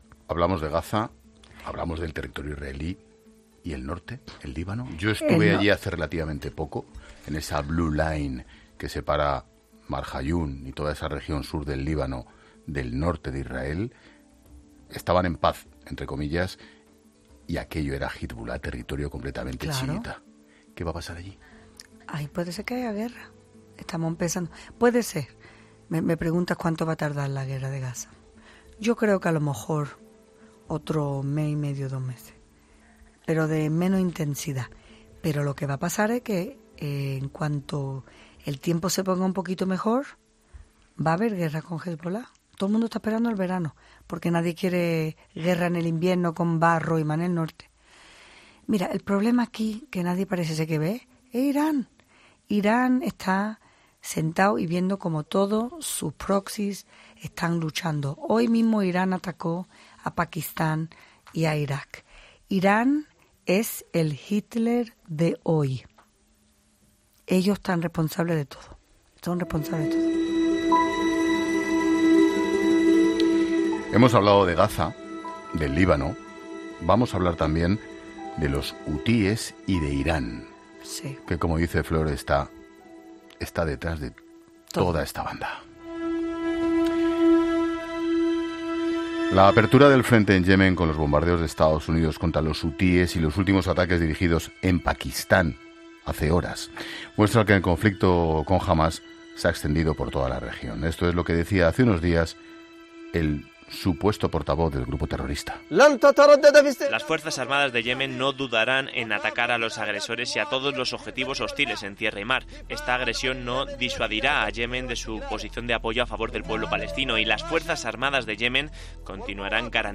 Una entrevista en la que la viceregidora de la capital israelí denunciaba el funcionamiento del sistema educativo en Gaza.